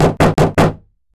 Doors